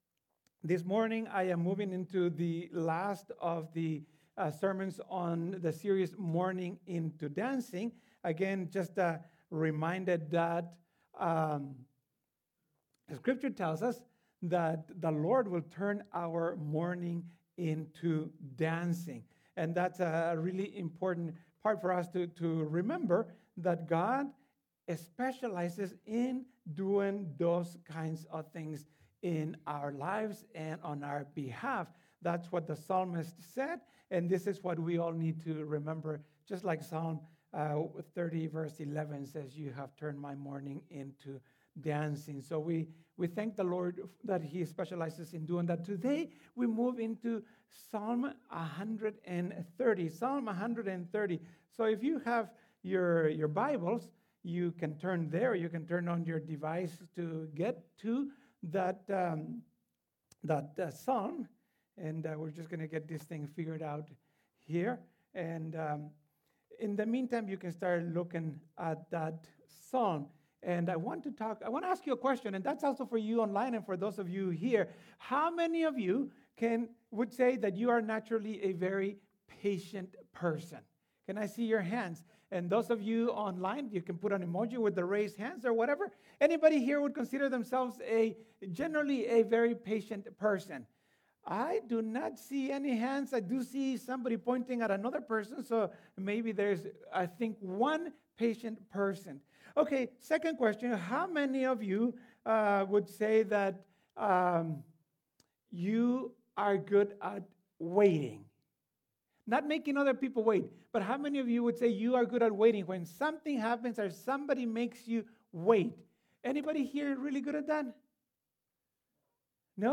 This is the last sermon in the Mourning Into Dancing series.